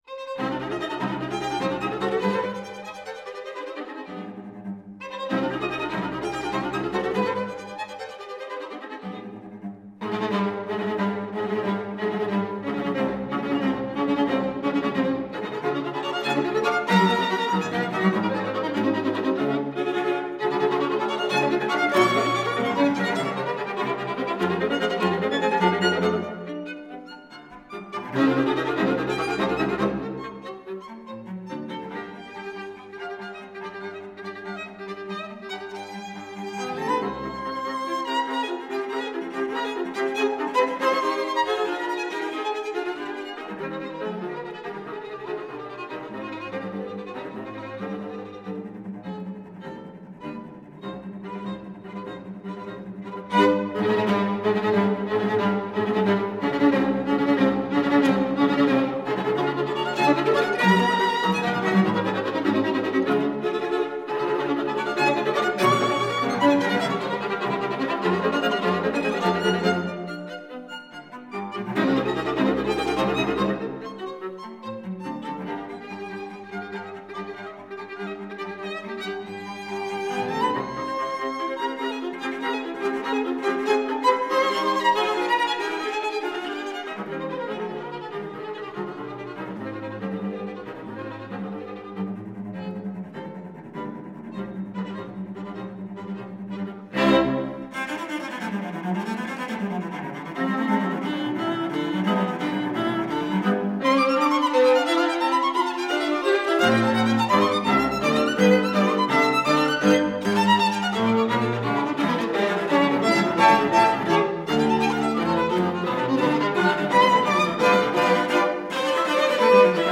first violin
second violin
viola
cello